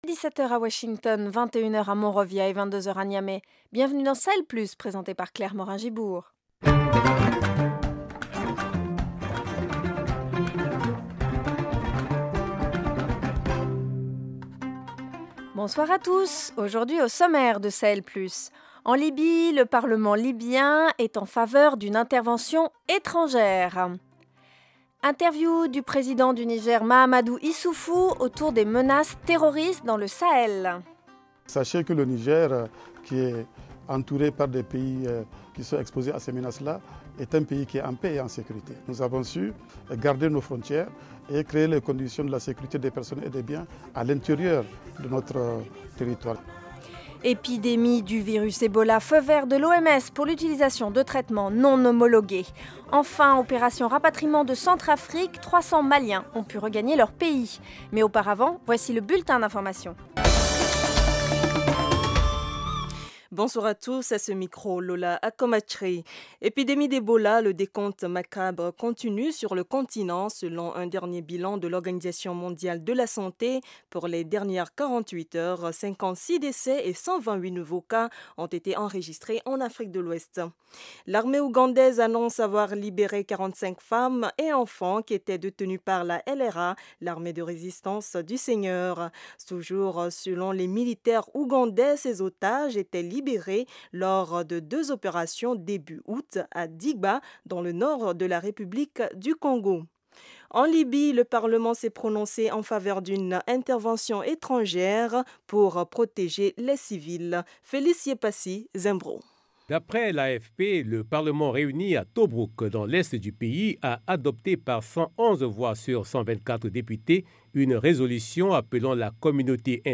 Au programme : en Libye, le parlement en faveur d’une intervention étrangère. Interview du président du Niger Mahamadou Issoufou autour des menaces terroristes dans le Sahel. Virus Ebola: feu vert de l’OMS pour l’utilisation de traitements non homologués.